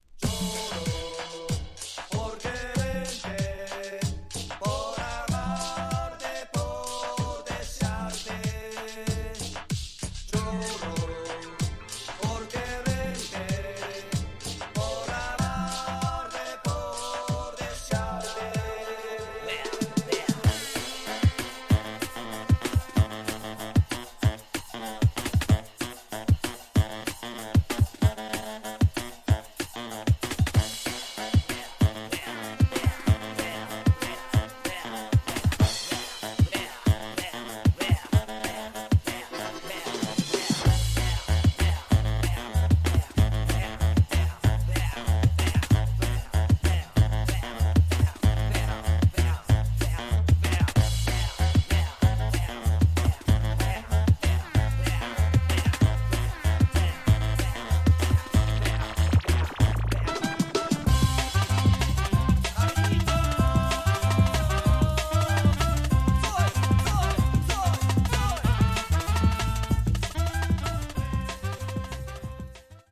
Tags: Japan , Cumbia , Remix